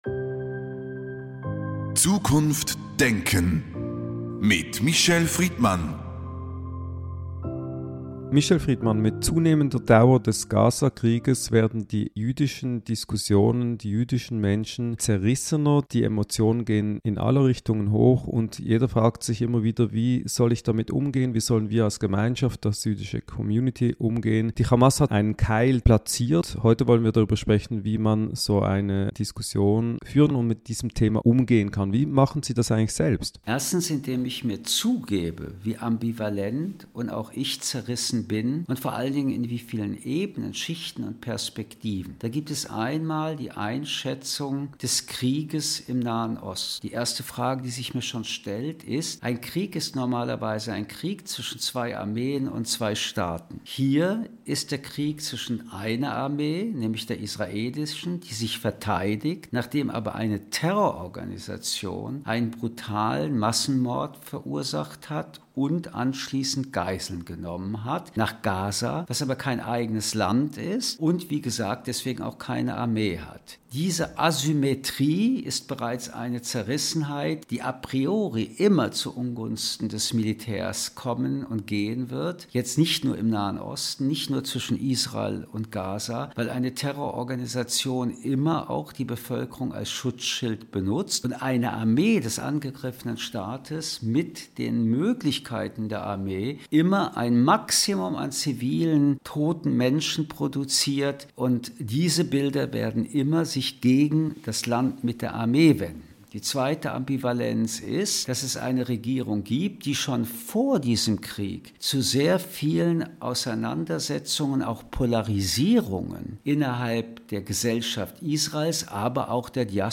Der fortdauernde Krieg im Gazastreifen wird immer grausamer und hat mit der Operation in Rafah einen neuen Höhepunkt erreicht. Michel Friedman spricht in der aktuellen Ausgabe «Zukunft denken» über die innere Zerissenheit in Teilen der jüdischen Gemeinschaften.